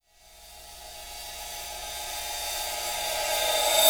Track 08 - Reverse Cymbal OS 01.wav